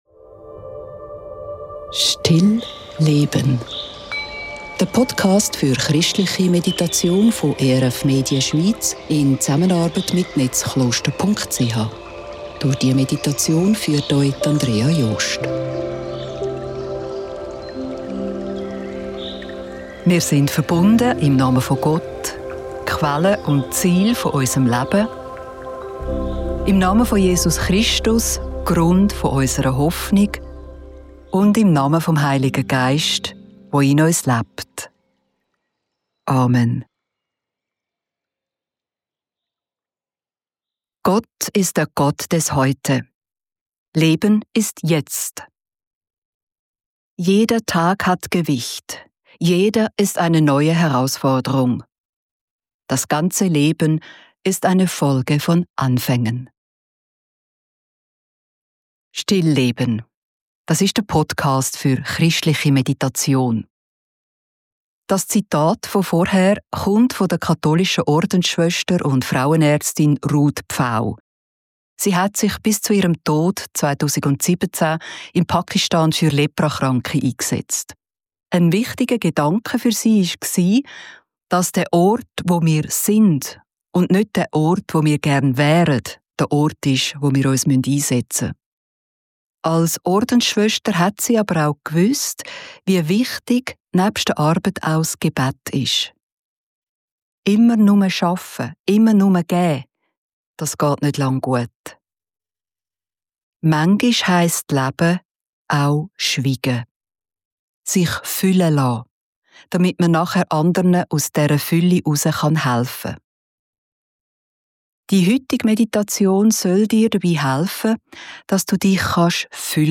Die geführte Meditation soll dir dabei helfen, dass du dich füllen lassen und Kraft bei Gott schöpfen kannst. Durch die Meditation führen uns Gedankenbilder des bekannten christlichen Mystikers Bernhard von Clairvaux.